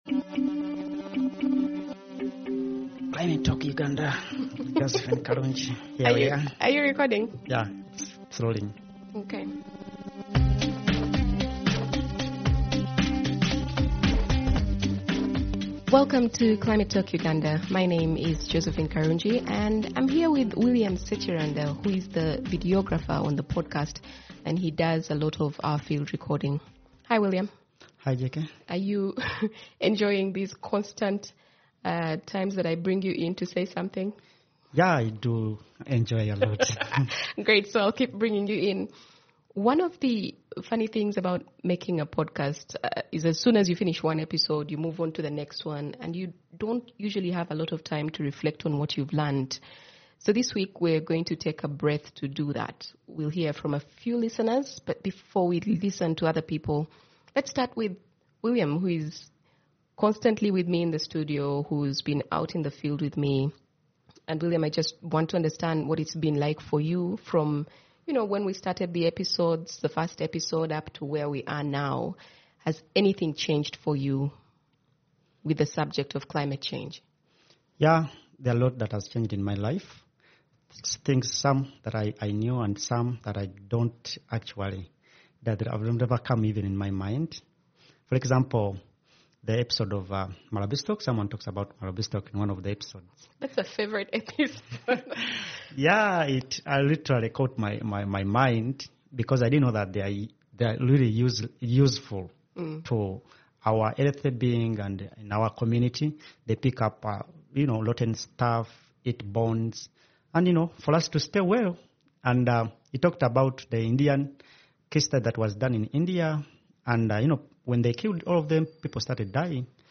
Deep stuff in this conversation. As a prelude to the next few episodes that'll explore climate change, infrastructure and transportation, we touch on the background to climate change in Uganda; agriculture, engineering and bridges; organisational thinking, the politics of climate change and economic justice.